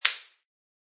plasticplastic3.mp3